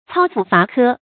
操斧伐柯 cāo fù fá kē
操斧伐柯发音